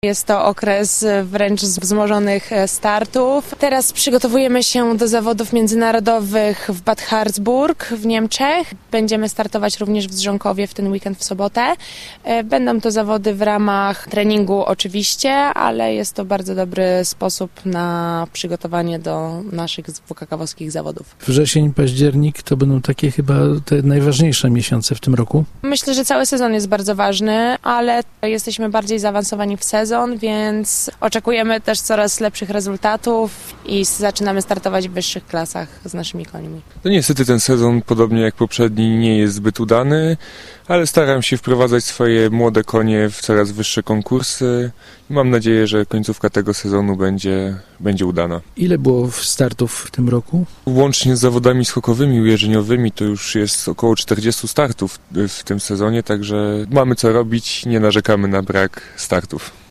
Rozmowa z jeździeckim rodzeństwem reprezentującym Zielonogórski Klub Sportowy w niedzielnej audycji Muzyka i sport.